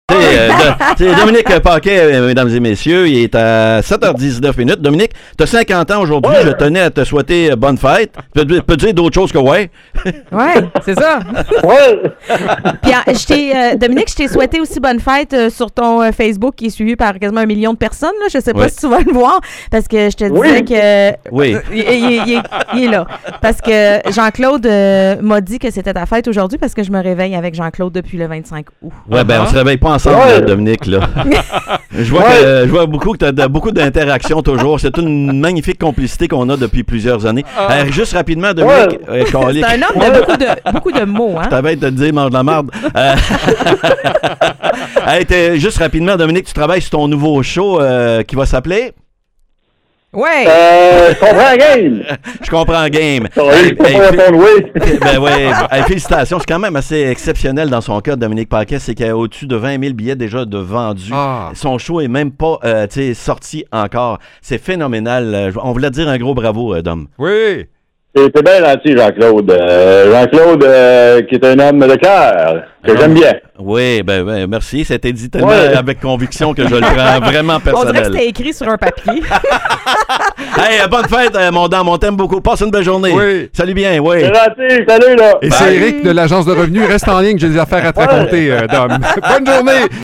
en lui téléphonant en direct pour lui souhaiter un joyeux anniversaire !